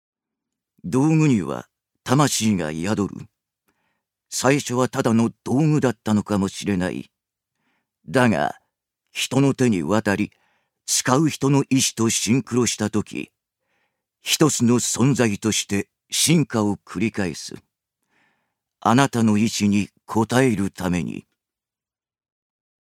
ジュニア：男性
ナレーション２